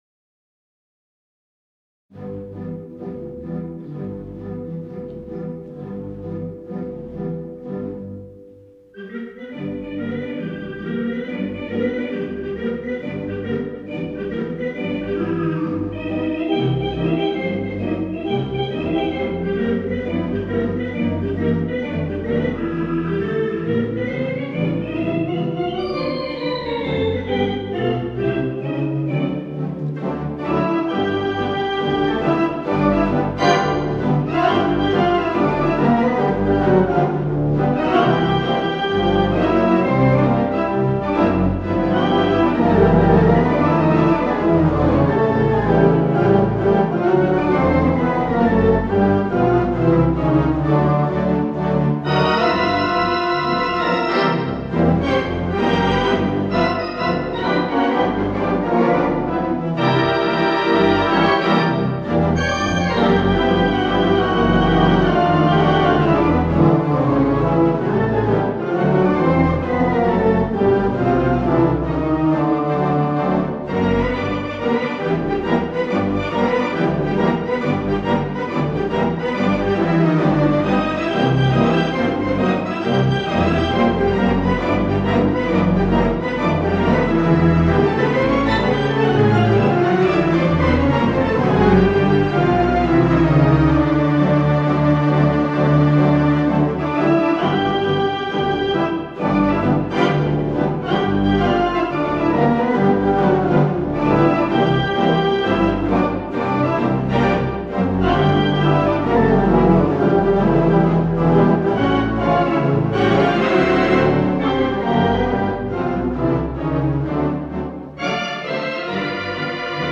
Theatre Pipe Organ